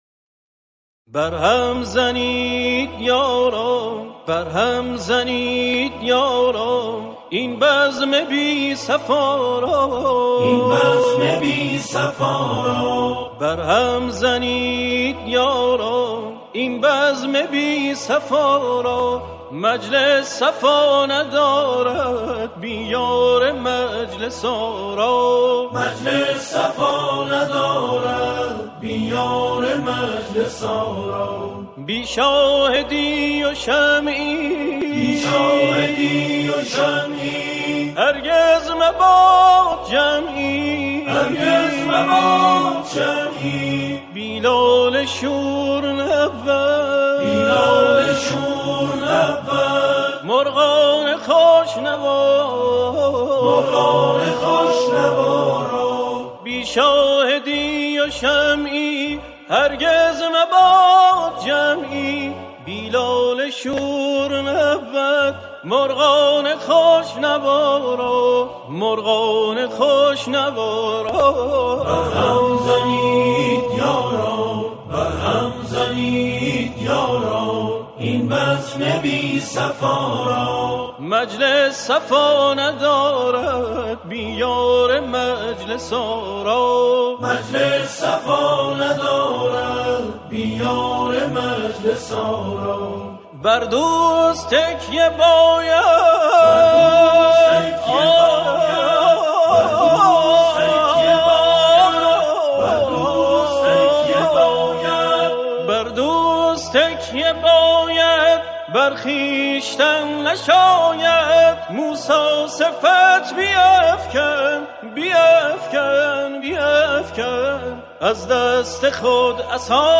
آکاپلا
گروهی از همخوانان
این اثر به صورت آکاپلا اجرا شده است.